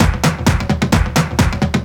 Index of /90_sSampleCDs/Ueberschall - Techno Trance Essentials/02-29 DRUMLOOPS/TE20-24.LOOP-ADDON+HIHAT/TE20.LOOP-ADDON2